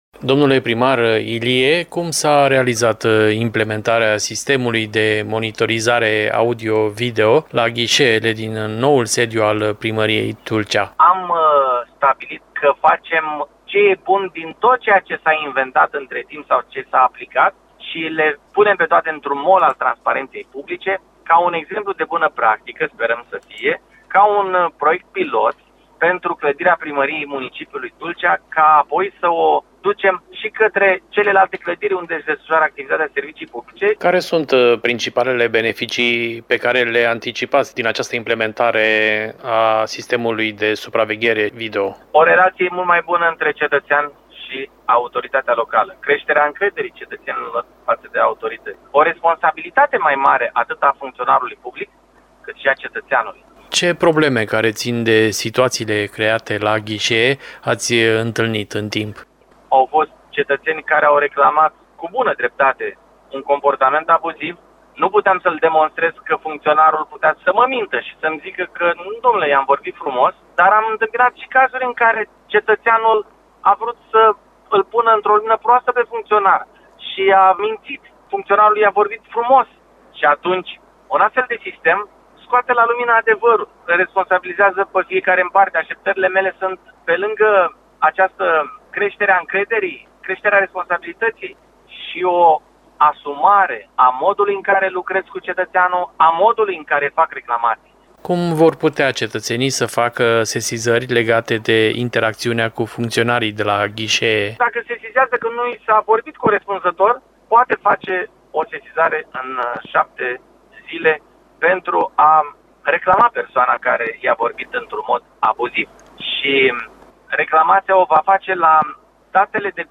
din discuția cu primarul Ștefan Ilie